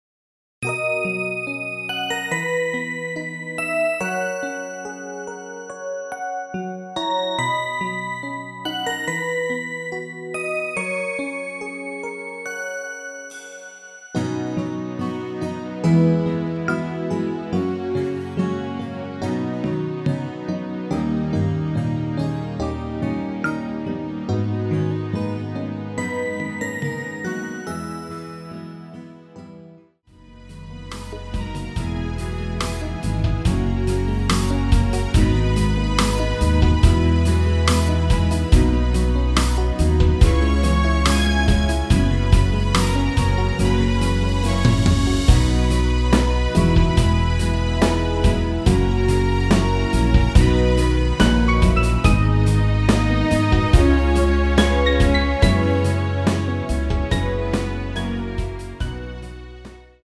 (-1) MR 입니다.
2절과 간주 없이 바로 진행이 됩니다.
<간주 없이 아래 가사로 바로 진행 됩니다.>
앞부분30초, 뒷부분30초씩 편집해서 올려 드리고 있습니다.
중간에 음이 끈어지고 다시 나오는 이유는